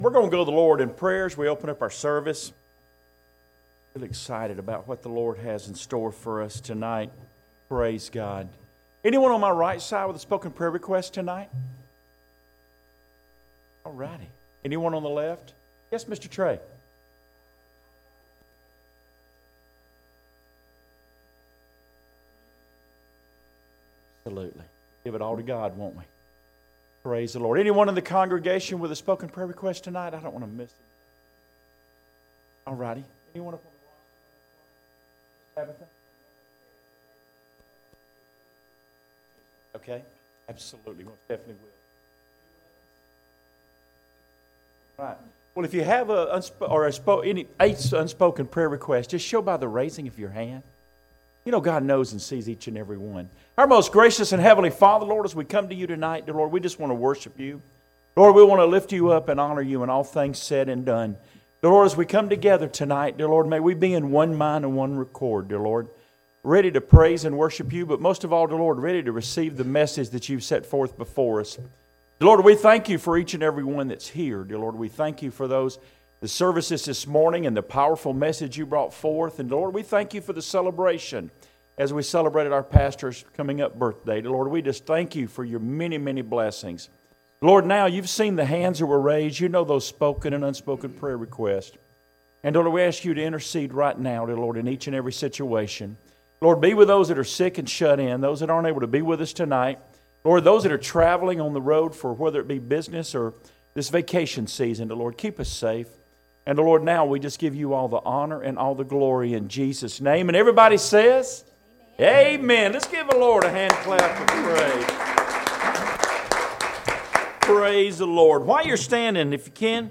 Daniel 3:23" Service Type: Sunday Evening Services « Have We Left Our First Love?